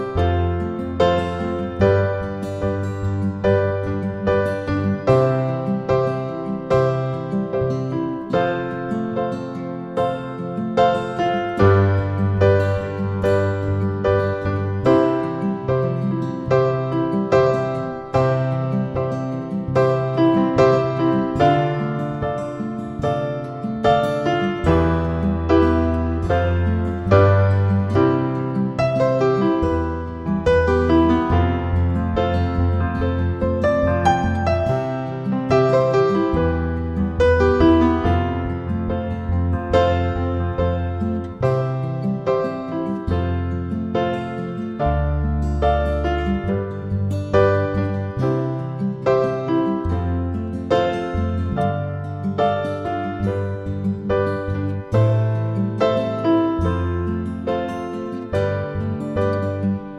Duet Version Pop (2010s) 3:47 Buy £1.50